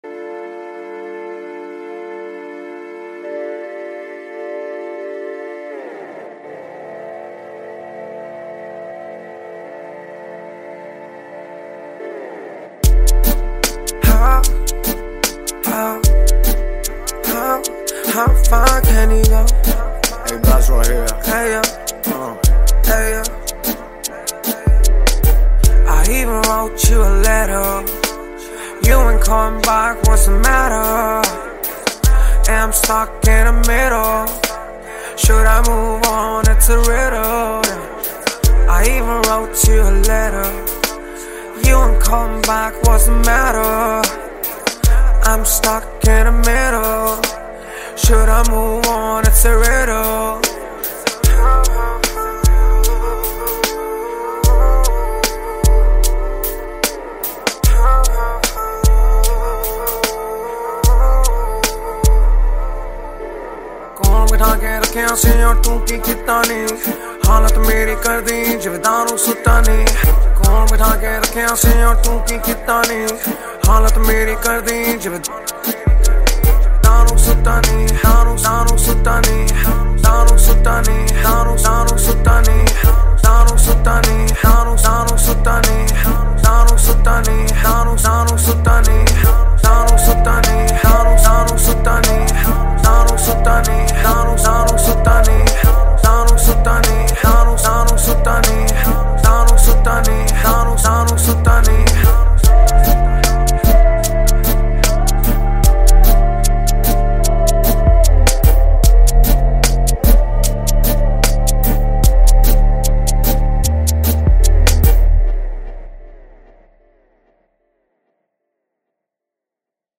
Category: Punjabi Single Songs